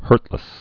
(hûrtlĭs)